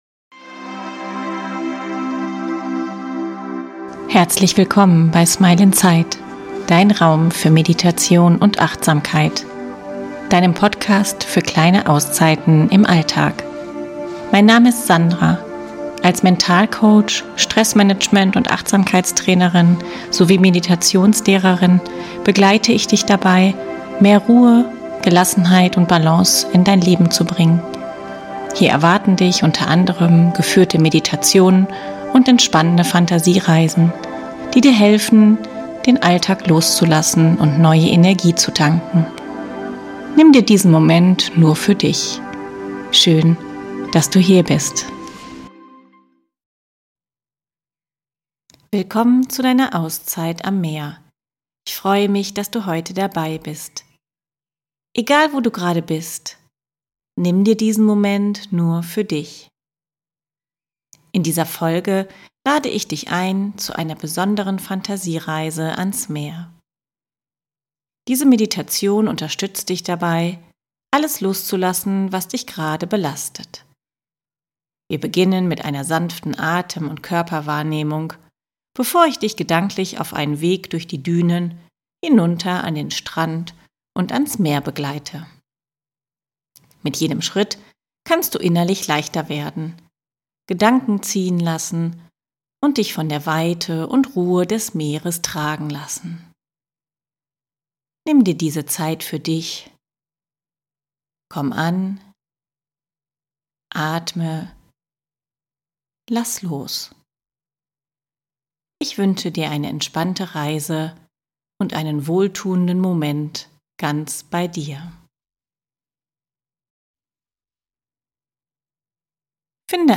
Diese Meditation unterstützt dich dabei, alles loszulassen, was dich gerade belastet. Wir beginnen mit einer sanften Atem- und Körperwahrnehmung, bevor ich dich gedanklich auf einen Weg durch die Dünen, hinunter an den Strand und ans Meer begleite.